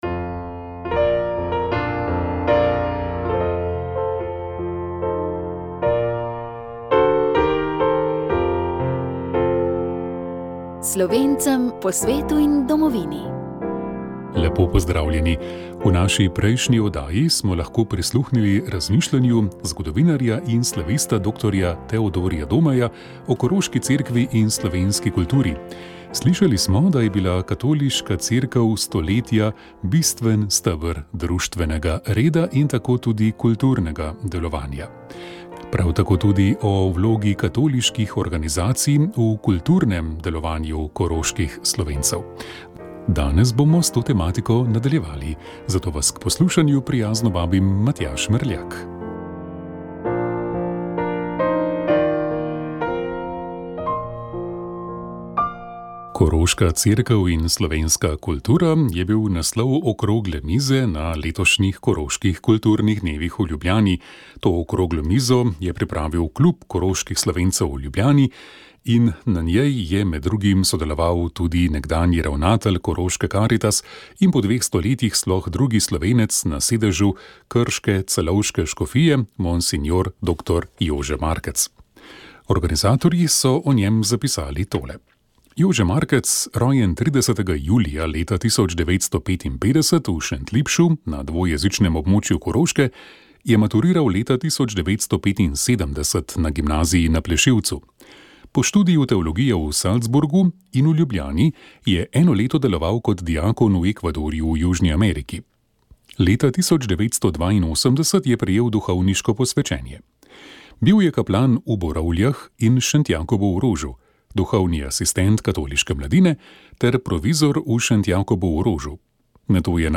Sv. maša iz podružnične cerkve sv. Ane, Boreča